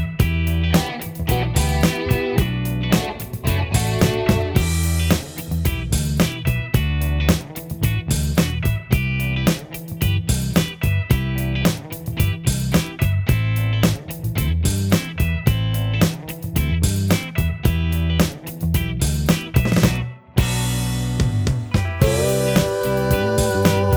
With Low Guitar Part Only Soft Rock 4:53 Buy £1.50